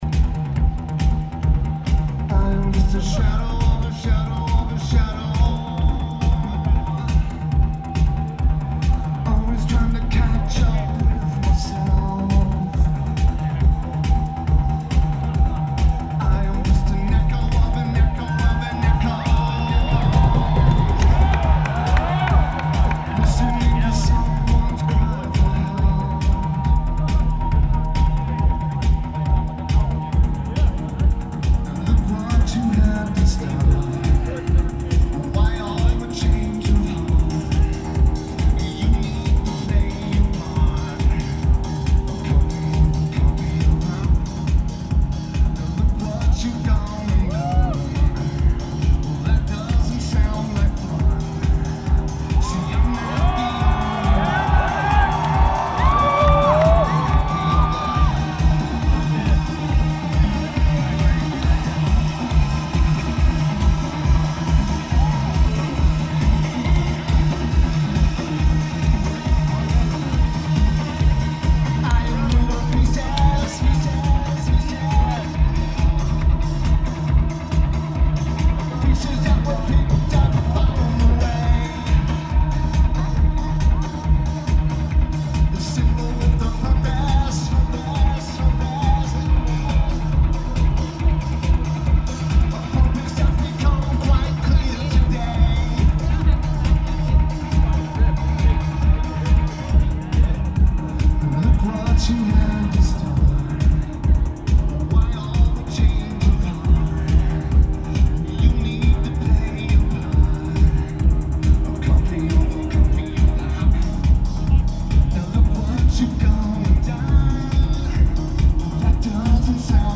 Lineage: Audio - AUD (Sony ECM-717 + Sony TCD-D8)
Notes: Average recording.